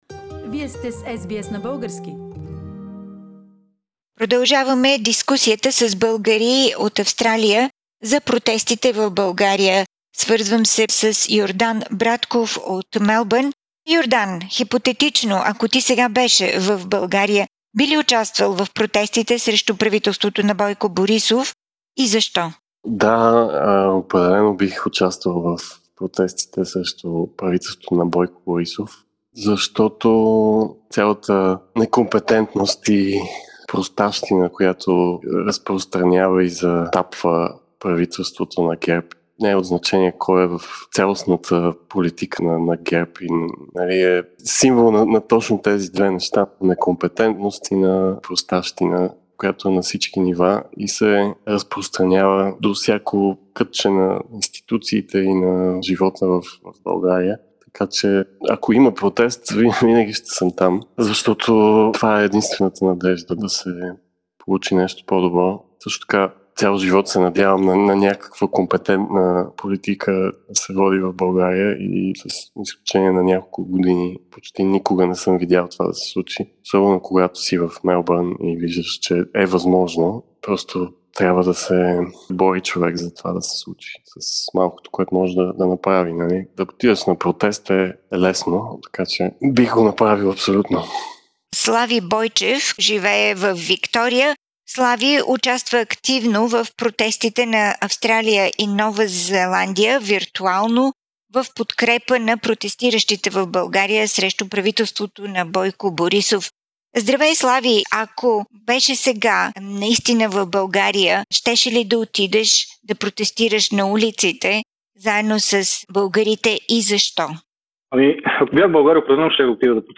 Българи от Австралия за анти-правителствените протести в България